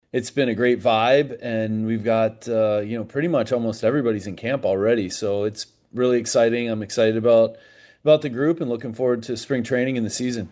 Kelly and Cherington spoke on a Grapefruit League conference call and both addressed the Andrew McCutchen situation.